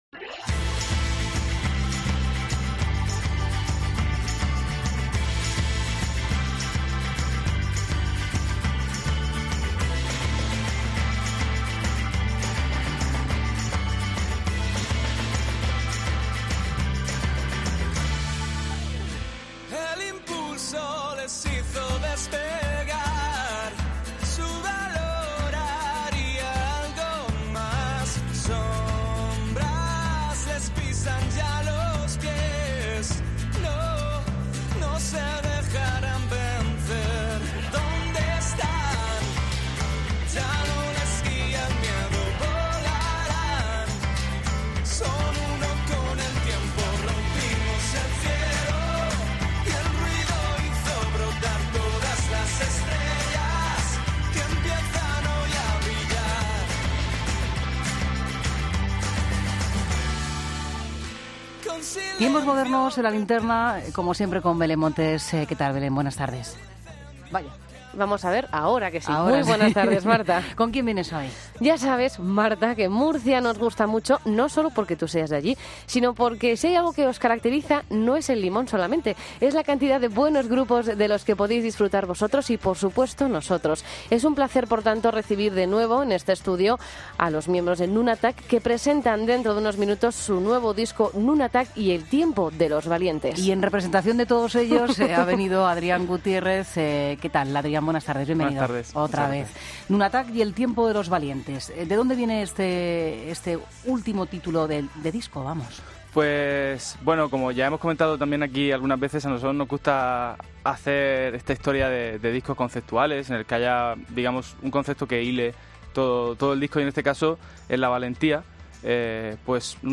Entrevista a Nunatak en La Linterna